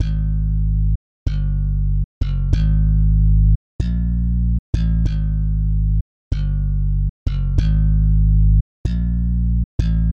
描述：低音循环